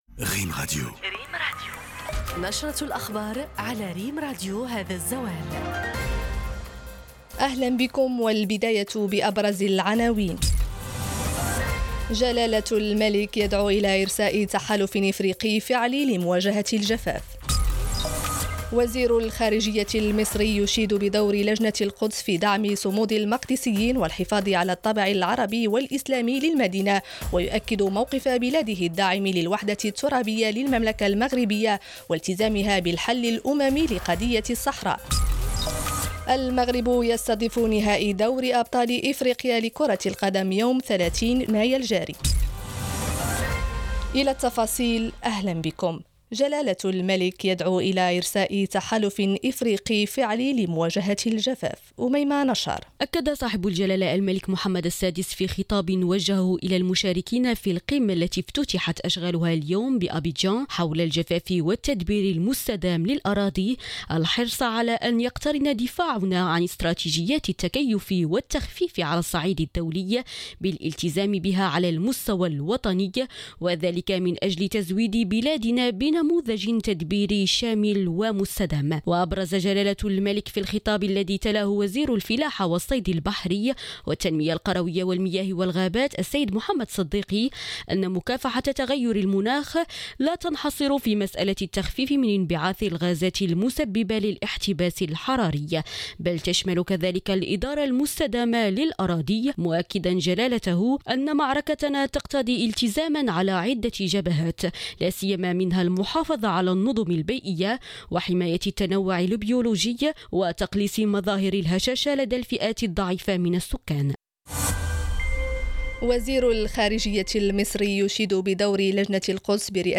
نشرة الظهيرة